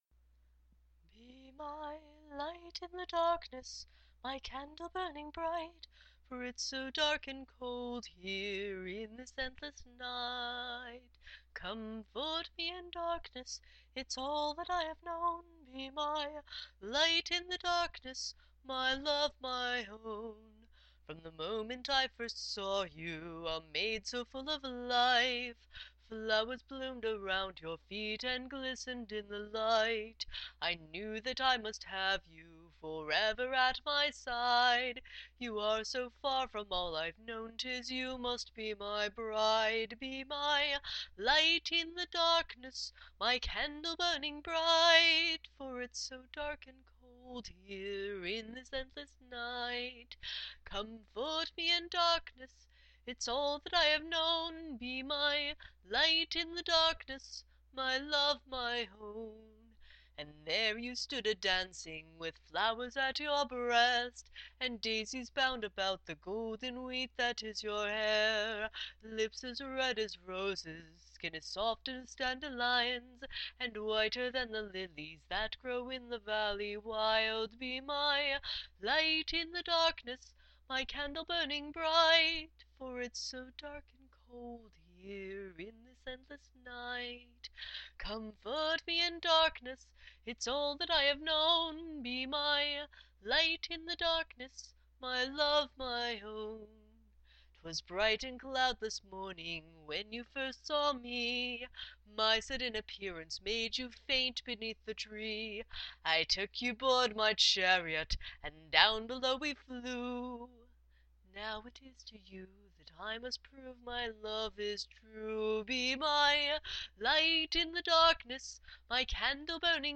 Light-in-the-Darkness-Rough-Cut.mp3